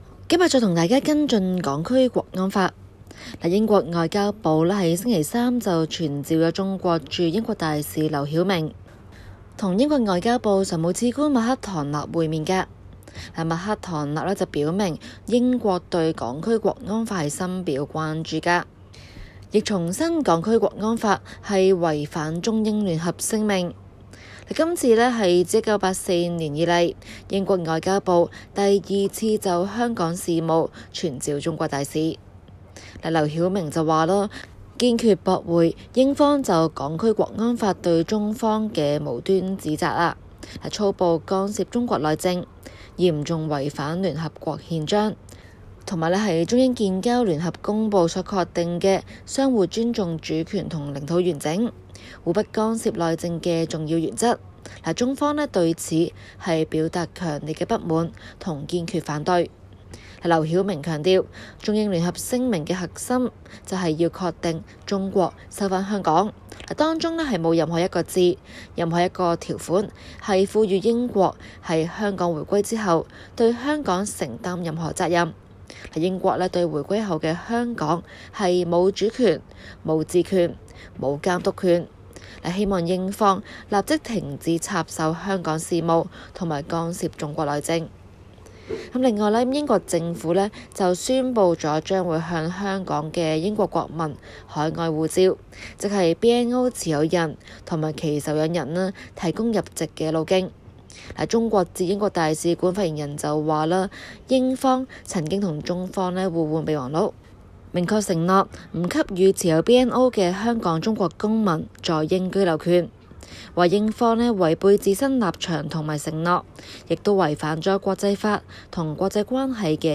今期【中港快訊】環節報道香港警方確證在甚麽情況下屬觸犯【國安法】，以及中英就【國安法】展開外交爭辯。